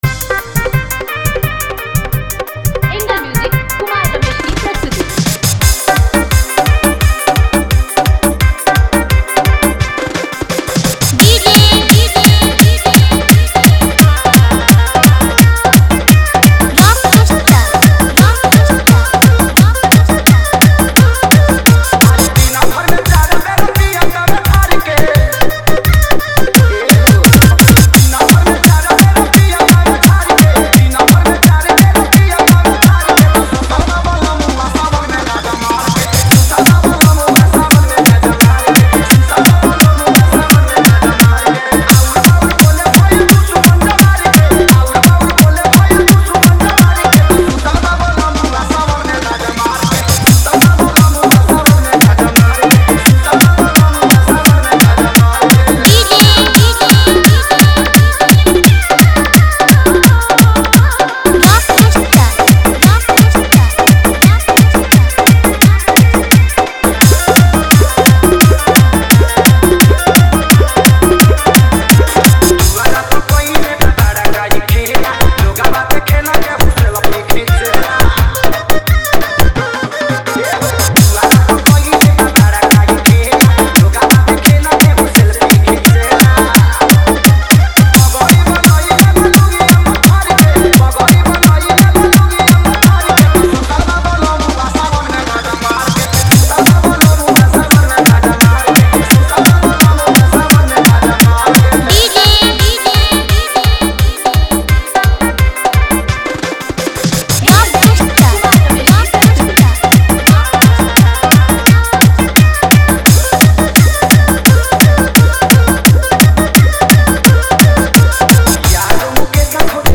Category:  Bol Bam 2021 Dj Remix Songs